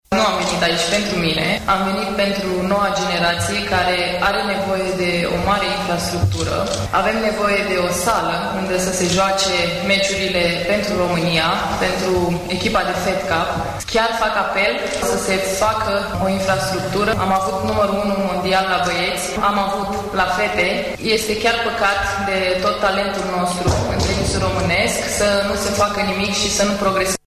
Simona Halep, numărul 2 WTA, a vorbit despre nevoia acută de săli de sport şi implicarea autorităţilor în sprijinirea financiară a tinerilor sportivi :